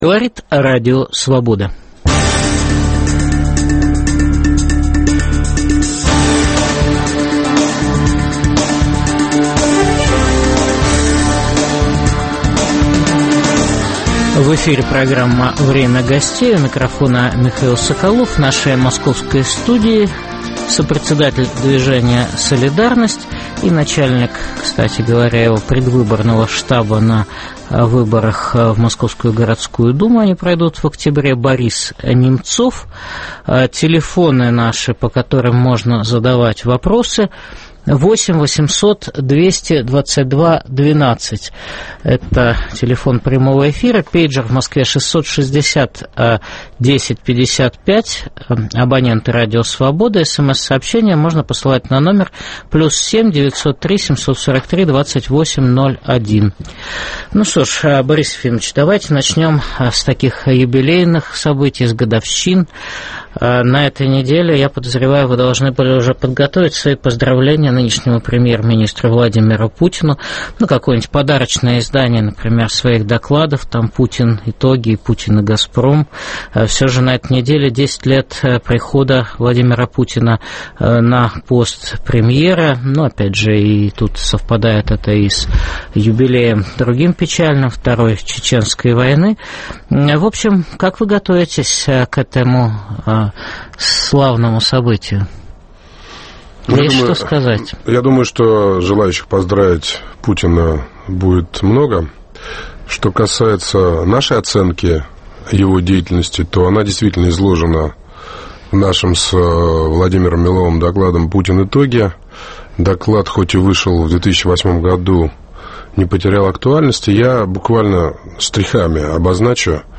В программе выступит сопредседатель движения "Солидарность", руководитель его штаба на выборах в Московскую городскую думу Борис Немцов.